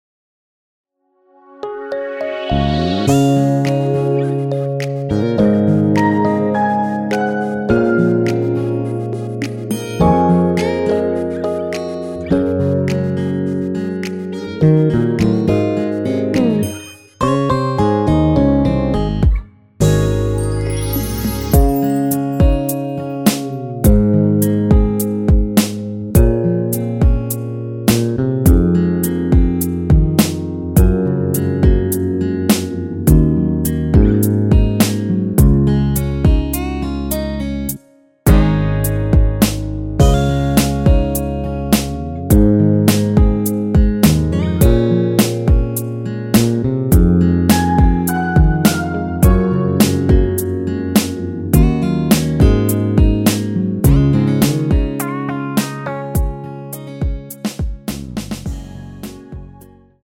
원키에서(-1)내린 1절후 후렴(2절삭제)으로 진행되는 MR 입니다.(본문 가사 참조)
Db
앞부분30초, 뒷부분30초씩 편집해서 올려 드리고 있습니다.
중간에 음이 끈어지고 다시 나오는 이유는